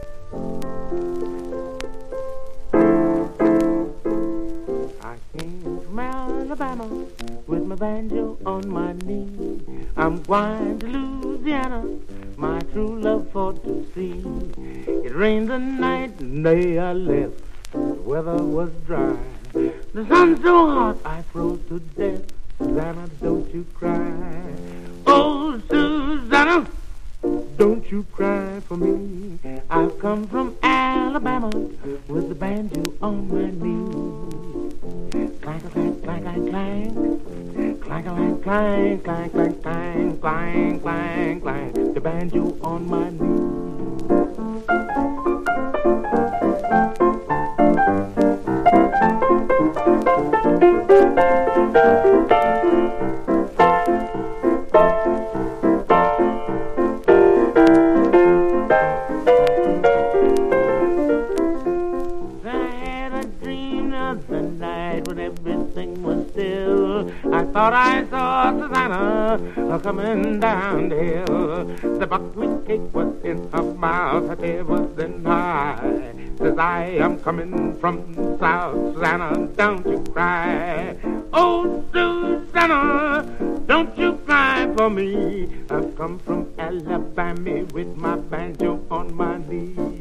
はずむように弾いて歌う、どこか愛嬌のある演奏。録音は1939年のNY。
VOCAL JAZZ
所によりノイズありますが、リスニング用としては問題く、中古盤として標準的なコンディション。